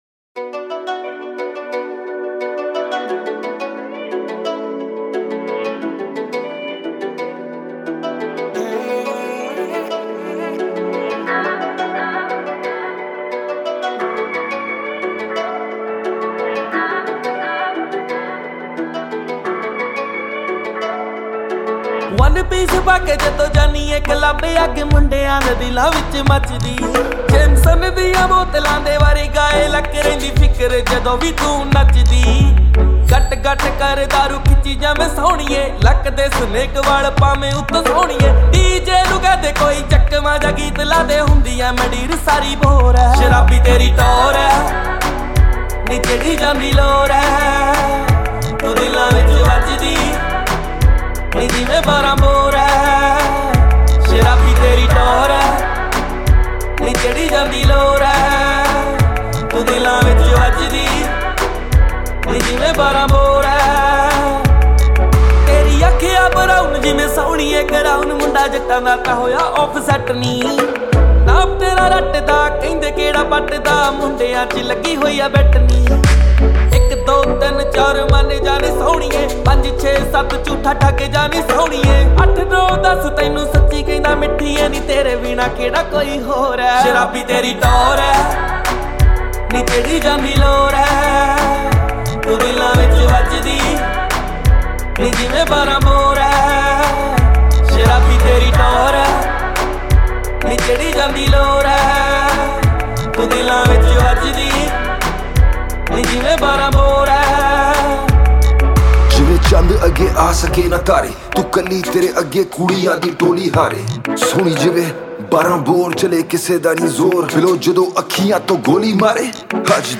2020 Punjabi Mp3 Songs
Punjabi Bhangra MP3 Songs